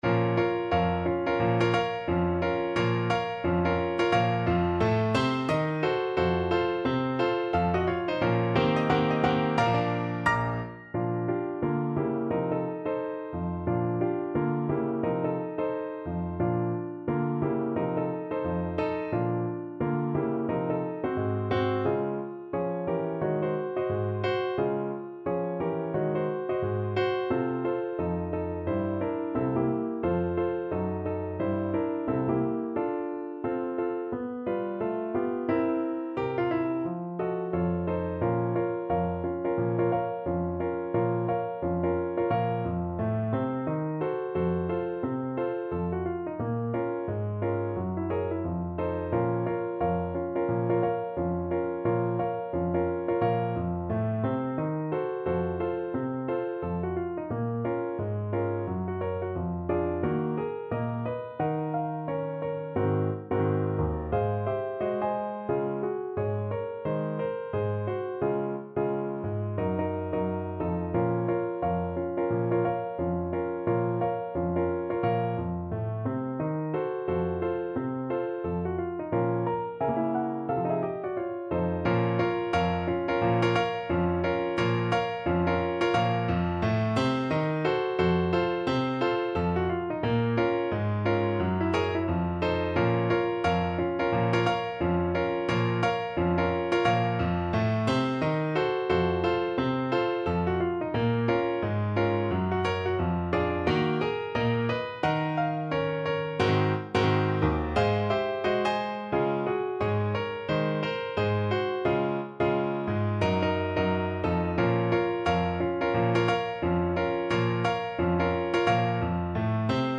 2/2 (View more 2/2 Music)
~ = 176 Moderato